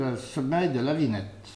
Langue Maraîchin
Patois - ambiance
Catégorie Locution